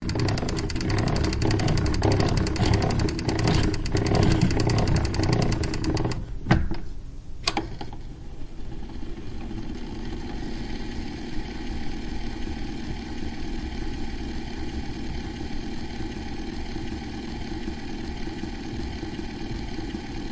Phonograph Wind up and Spin
yt_0mM7ffjPPlk_phonograph_wind_up_and_spin.mp3